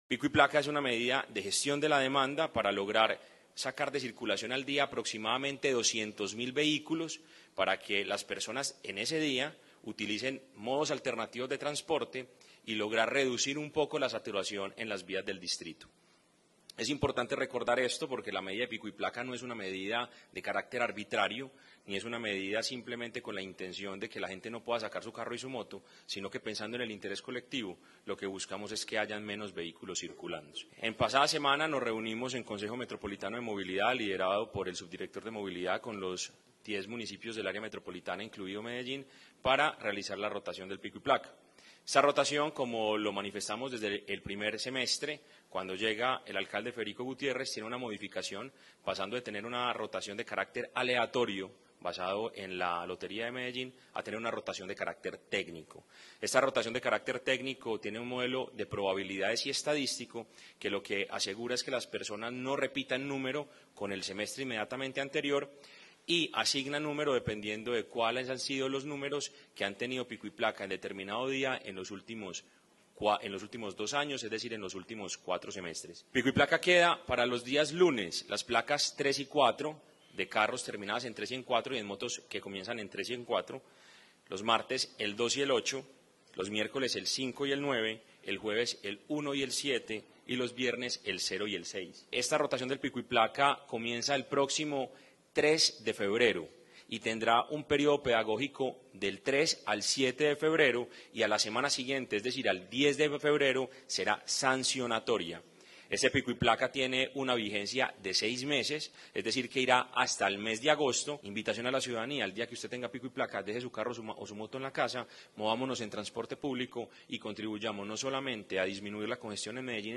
Palabras de Mateo González Benítez, secretario de Movilidad